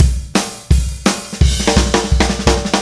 Indie Pop Beat 04 Fill A.wav